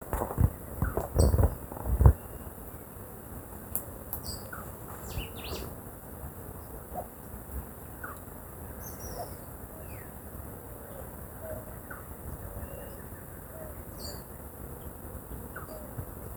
Crested Gallito (Rhinocrypta lanceolata)
Location or protected area: Parque Nacional El Impenetrable
Condition: Wild
Certainty: Observed, Recorded vocal
Gallito-copeton_1_1.mp3